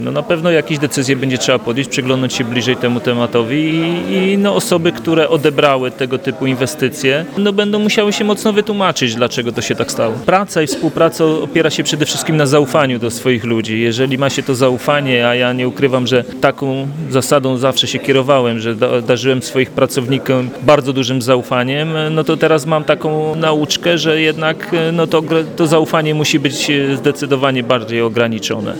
Zaskoczony tą prezentacją był prezydent Mielca Jacek Wiśniewski, który nie krył oburzenia takim stanem rzeczy.